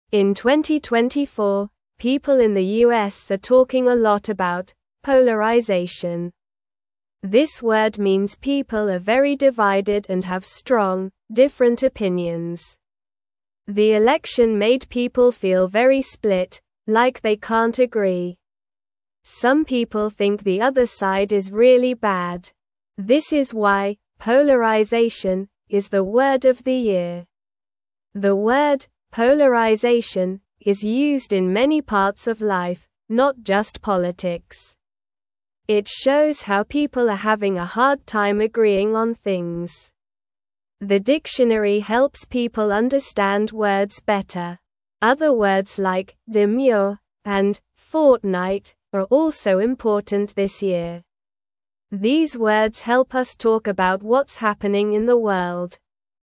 Slow
English-Newsroom-Beginner-SLOW-Reading-People-Are-Divided-The-Word-of-the-Year.mp3